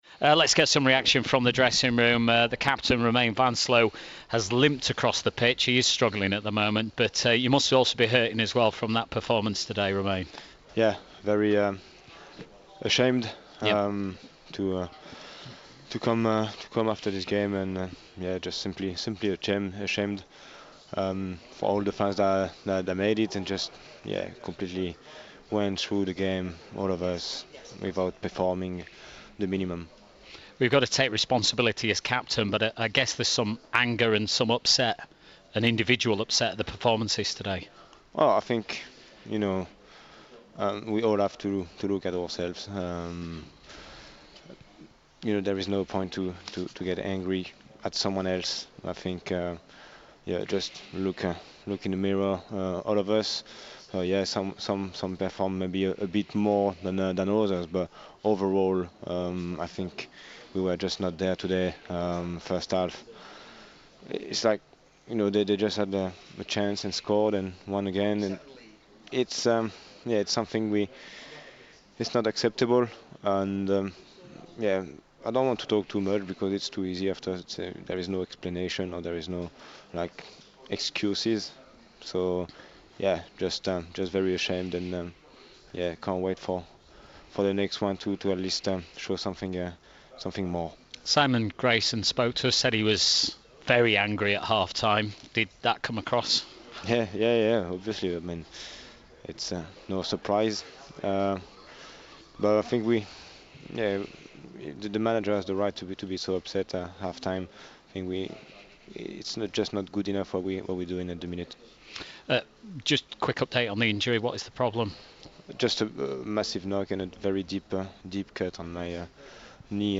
post match at Blackpool